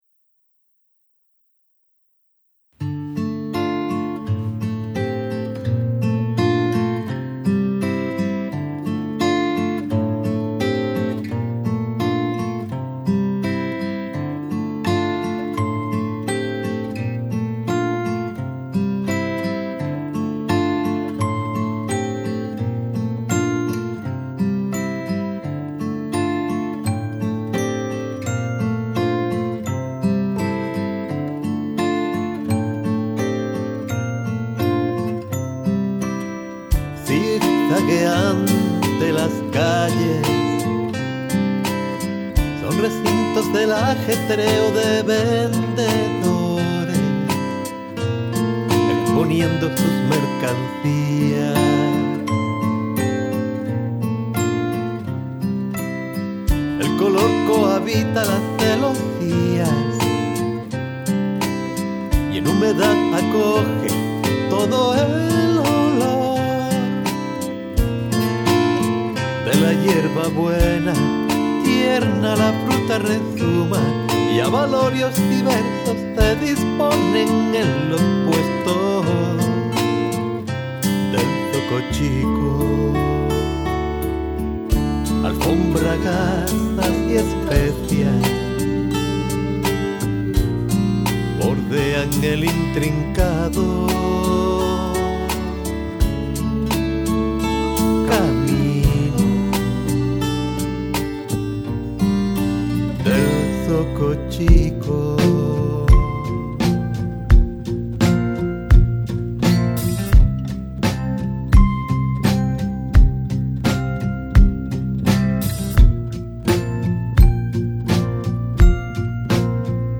Canción de autor